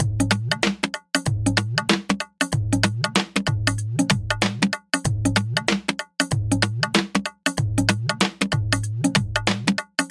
印度鼓
描述：迪安鼓
Tag: 95 bpm Ethnic Loops Drum Loops 1.70 MB wav Key : Unknown